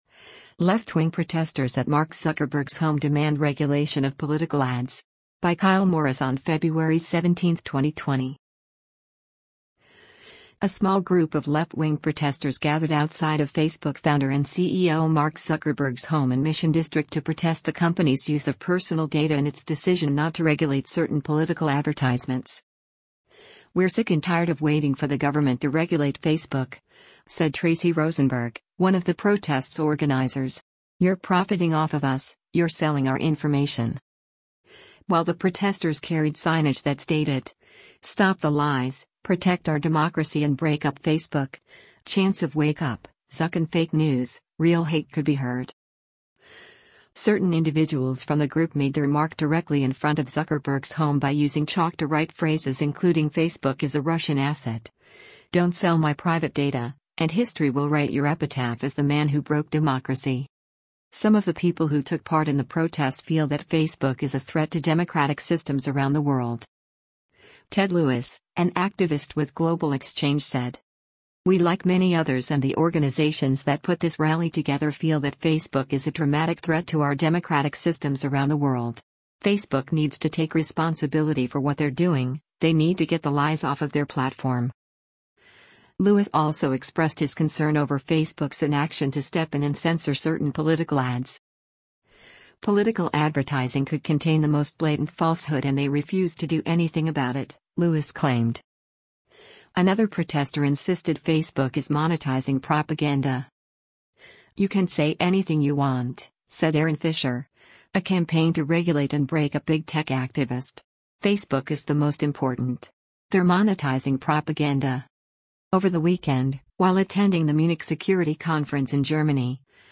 A small group of left-wing protesters gathered outside of Facebook founder and CEO Mark Zuckerberg’s home in Mission District to protest the company’s use of personal data and its decision not to regulate certain political advertisements.
While the protesters carried signage that stated, “Stop the Lies, Protect our democracy” and “break up Facebook,” chants of “Wake up, Zuck” and “fake news, real hate” could be heard.